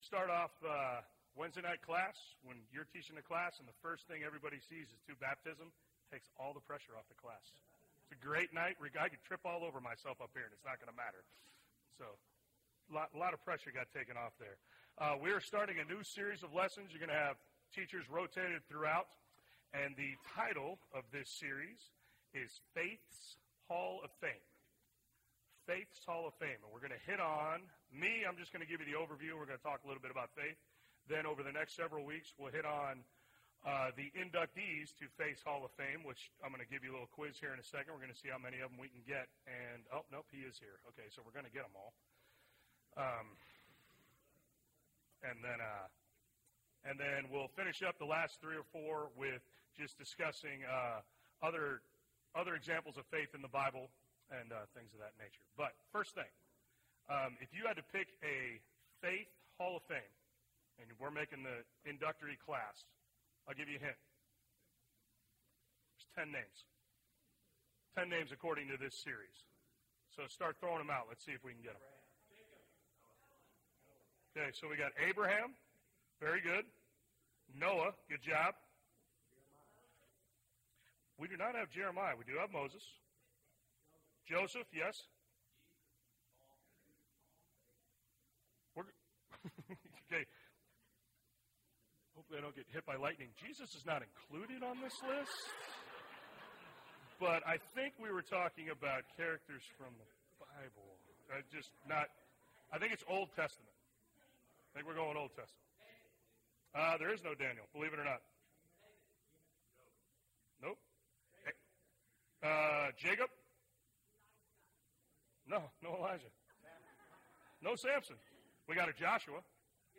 (1 of 10) – Bible Lesson Recording